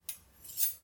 刀、剑碰撞声
描述：刀、剑碰撞声。
标签： 金属 格斗
声道立体声